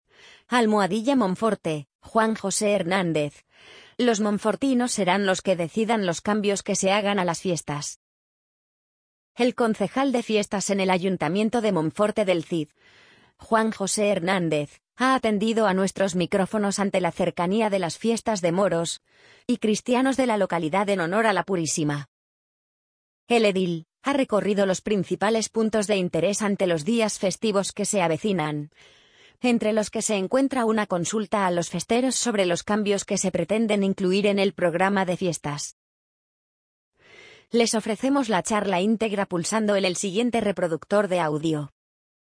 amazon_polly_62277.mp3